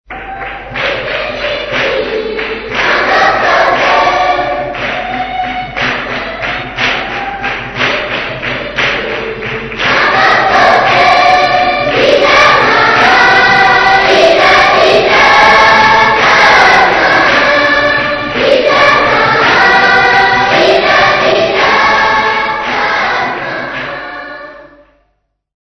Doringveld Congregation
Folk music
Field recordings
sound recording-musical
Easter Vigil Mass acclamation.
7.5 inch reel
96000Hz 24Bit Stereo